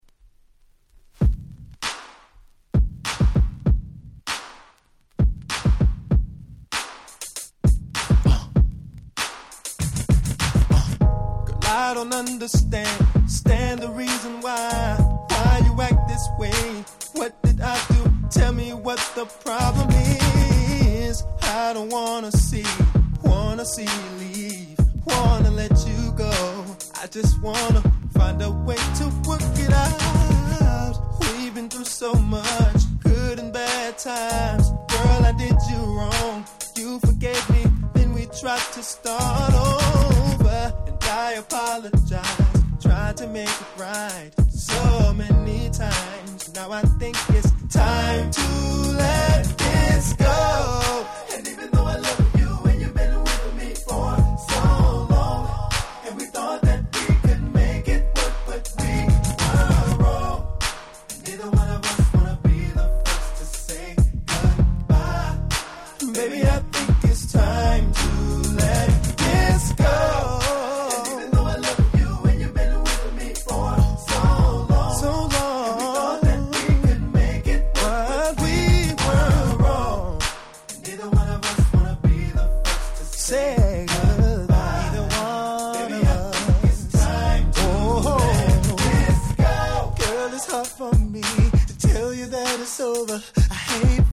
05' Smash Hit R&B !!